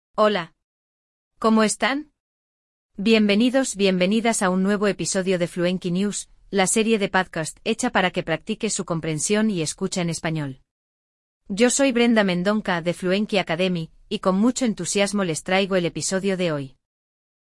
Ao longo do episódio, adicionamos explicações em português para garantir que você não perca nenhum detalhe.